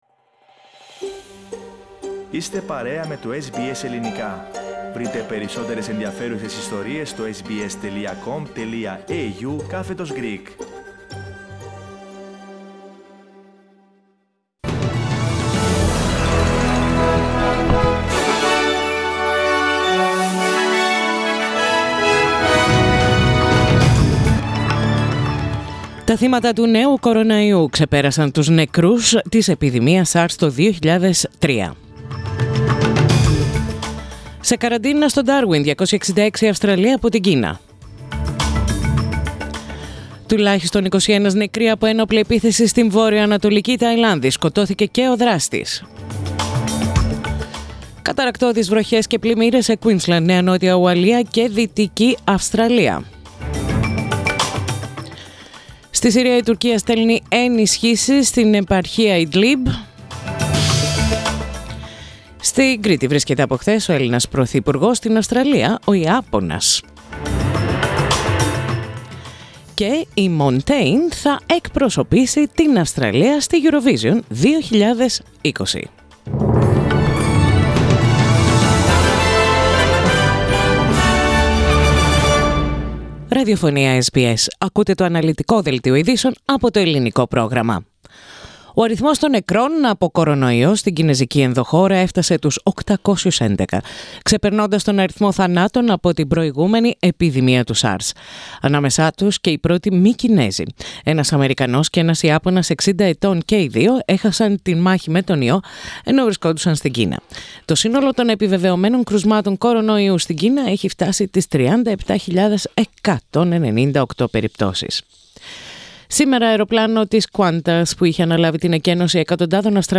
Main news of the the day from SBS Radio Greek.